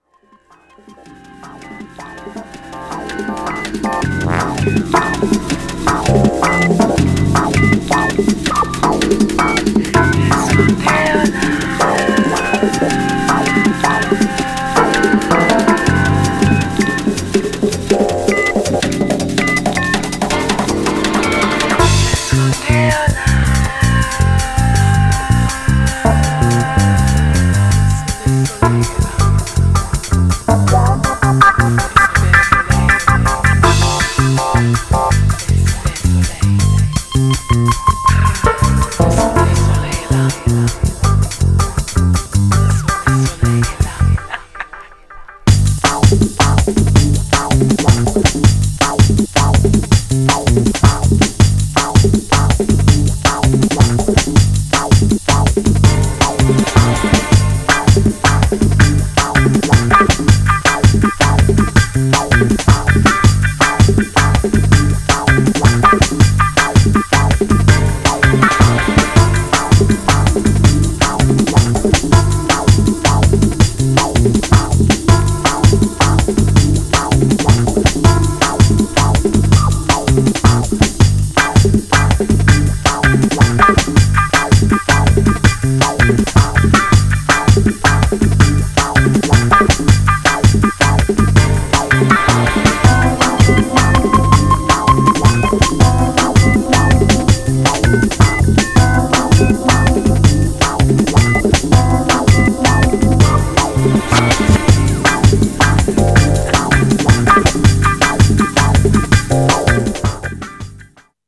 Styl: Drum'n'bass, House, Breaks/Breakbeat